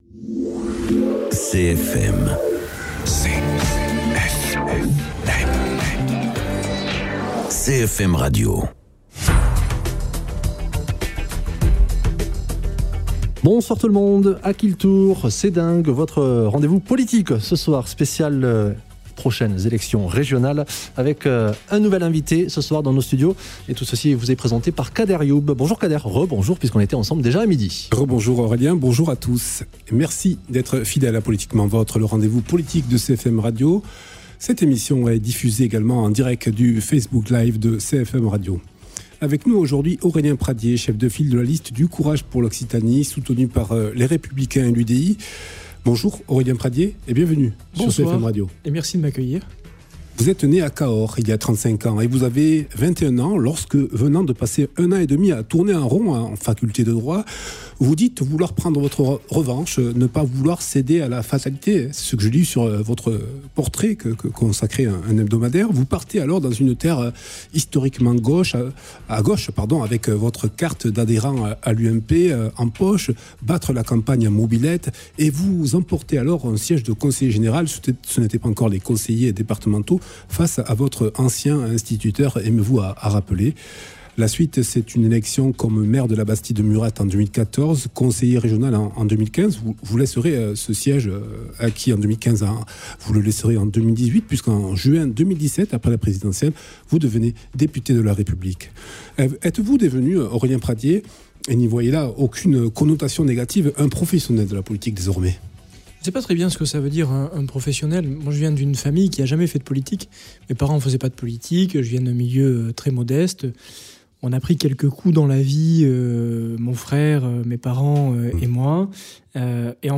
Invité(s) : Aurélien Pradié, tête de liste LR pour les régionales en Occitanie et député du Lot.